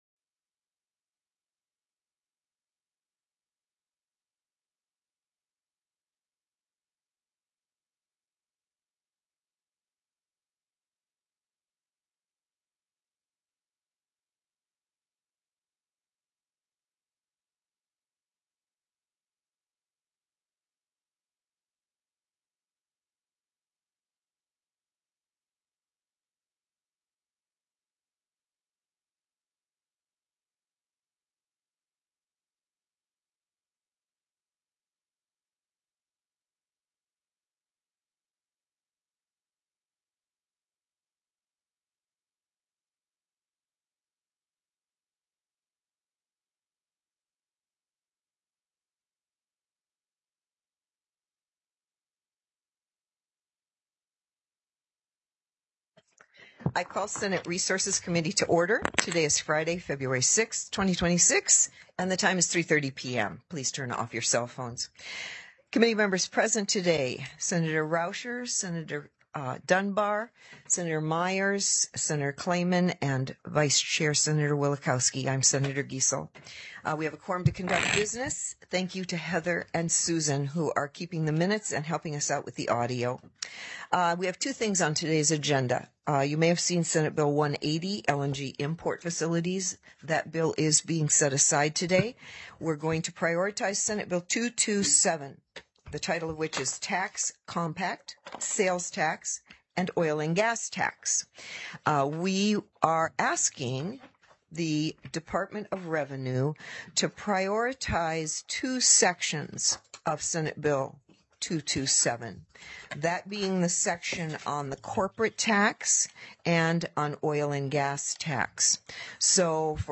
The audio recordings are captured by our records offices as the official record of the meeting and will have more accurate timestamps.
SB 227 TAX COMPACT; SALES TAX; OIL & GAS TAX TELECONFERENCED Heard & Held